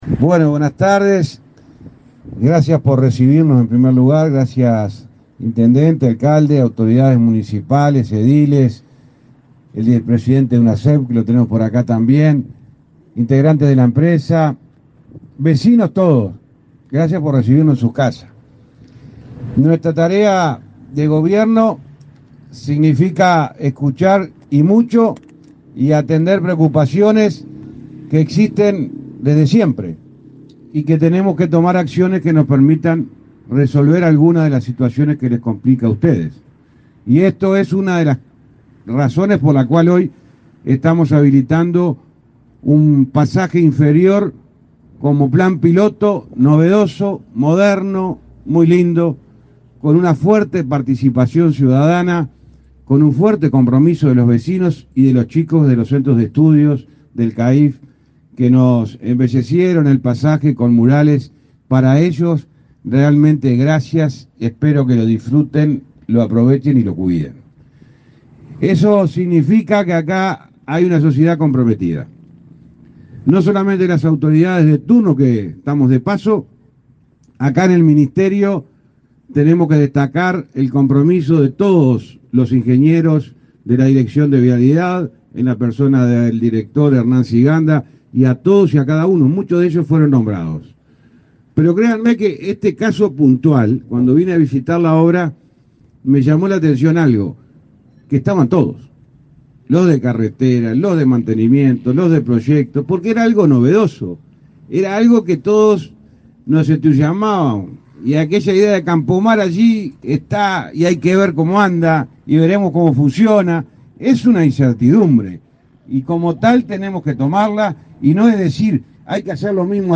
Palabras del ministro de Transporte y Obras Públicas, José Luis Falero
Palabras del ministro de Transporte y Obras Públicas, José Luis Falero 18/08/2022 Compartir Facebook X Copiar enlace WhatsApp LinkedIn El Ministerio de Transporte y Obras Públicas inauguró, este 18 de agosto, trabajos correspondientes a un proyecto piloto que incluye túneles en la ruta interbalnearia, en el departamento de Canelones. El ministro Falero participó en el evento.
falero oratoria.mp3